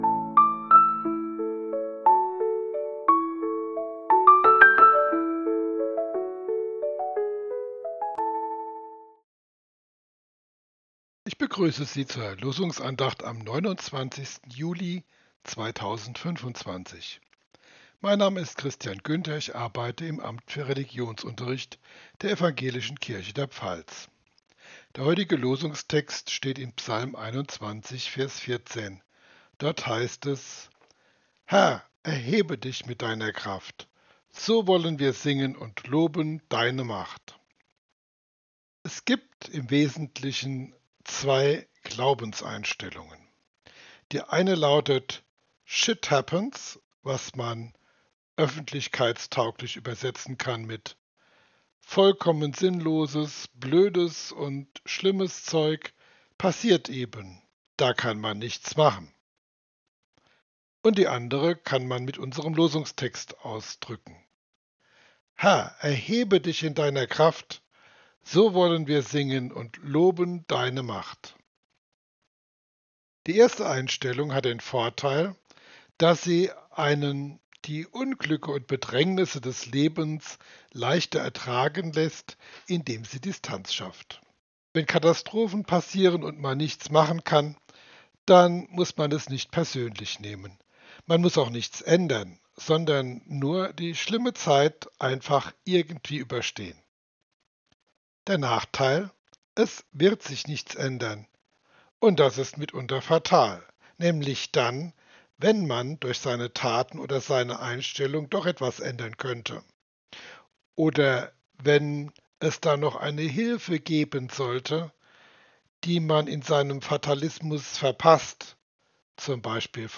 Losungsandacht für Dienstag, 29.07.2025